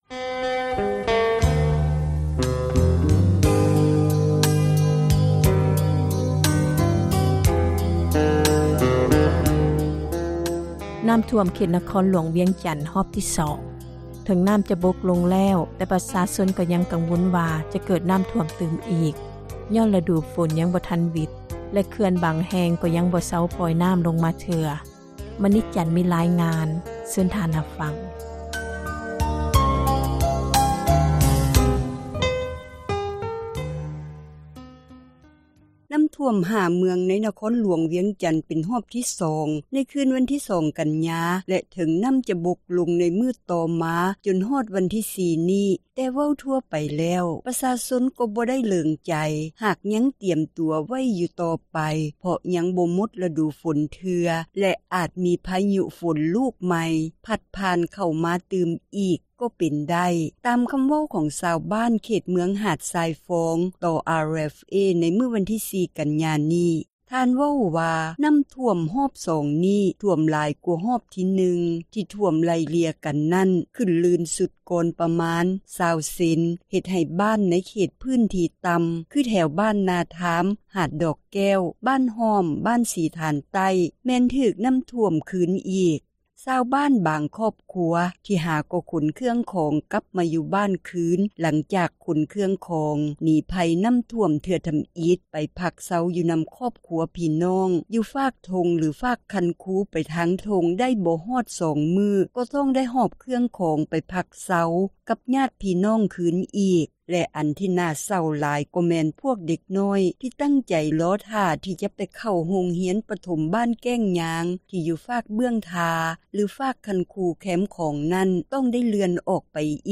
ນໍ້າຖ້ວມ 5 ເມືອງໃນນະຄອນຫລວງວຽງຈັນ ເປັນຮອບທີ 2 ໃນຄືນວັນທີ 2 ກັນຍາ ແລະ ເຖິງນໍ້າຈະບົກລົງ ໃນມື້ຕໍ່ມາຈົນມາຮອດວັນທີ 4 ນີ້ ແຕ່ເວົ້າທົ່ວໄປແລ້ວ ປະຊາຊົນກໍບໍ່ໄດ້ເຫຼີງໃຈ ຫາກຍັງຕຽມຕົວໄວ້ຢູ່ຕໍ່ໄປ ເພາະຍັງບໍ່ ໝົດຣະດູຝົນເທື່ອ ແລະ ອາດມີພາຍຸຝົນລູກໃໝ່ ພັດຜ່ານເຂົ້າມາ ຕື່ມອີກ ກໍເປັນໄດ້ພາຍຫລັງພະຍຸ 2 ລູກໄດ້ພັດຜ່ານ ເຂົ້າມາແລ້ວ. ຕາມຄໍາເວົ້າຂອງຊາວບ້ານເຂດເມືອງ ຫາດຊາຍຟອງ ທ່ານນຶ່ງ ຕໍ່ RFA ໃນມື້ວັນທີ 4 ກັນຍານີ້.